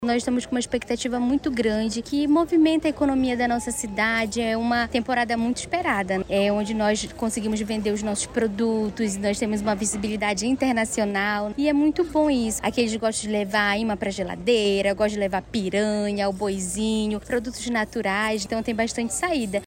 SONORA-3-TEMPORADA-CRUZEIROS-PARINTINS-.mp3